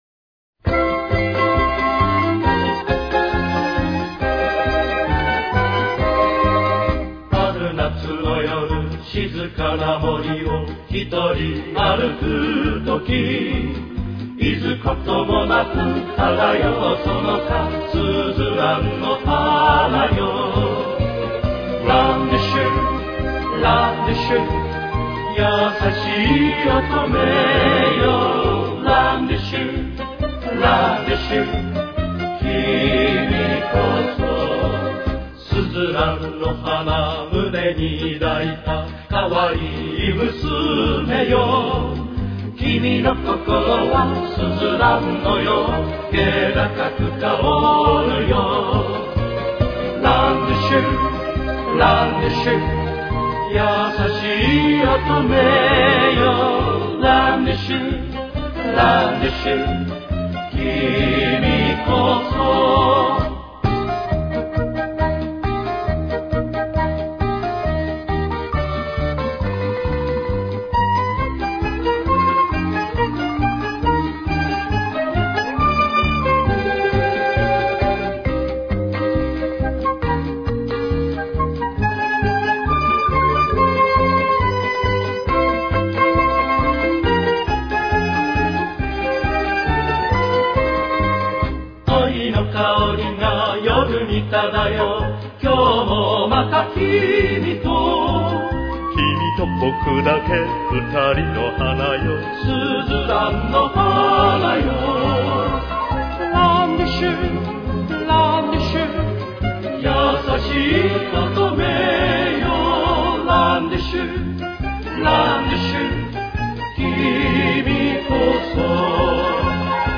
Япония)Тональность: Ми-бемоль минор. Темп: 140.